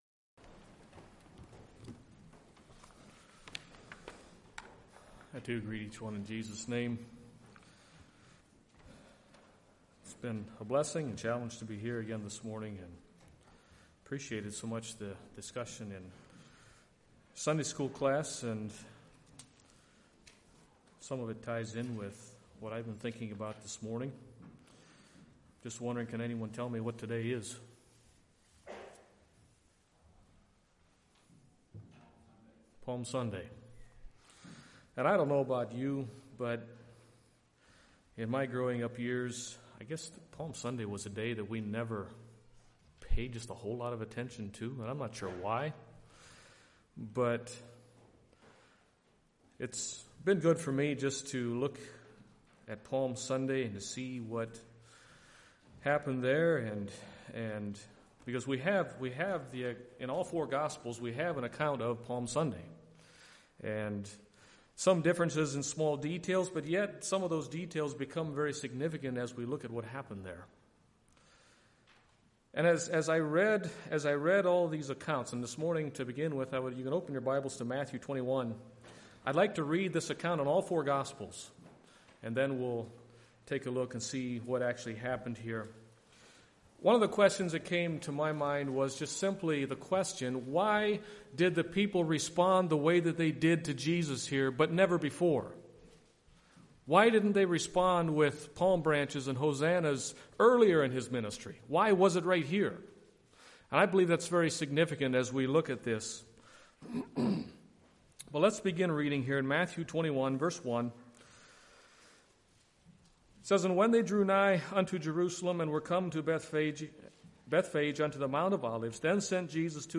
2022 Sermon ID